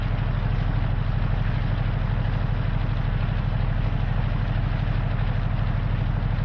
idle.ogg